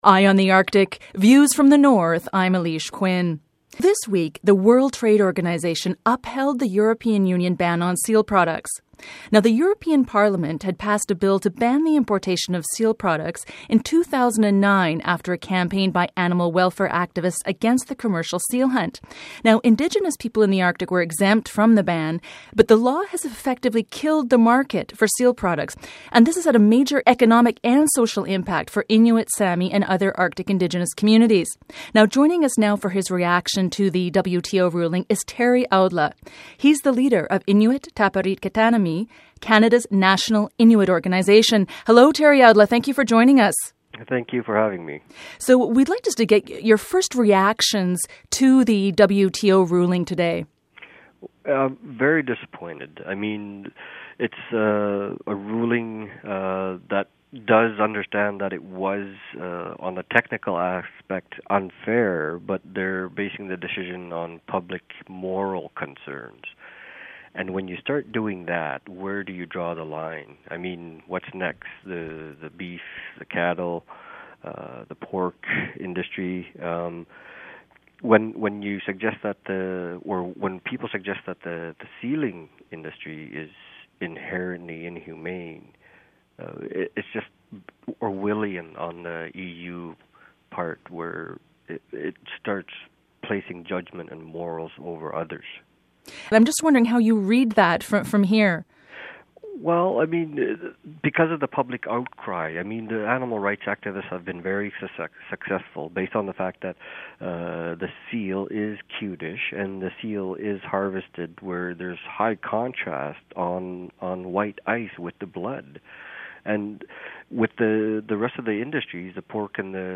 FEATURE INTERVIEW: Canadian Inuit leader denounces WTO ruling on EU seal ban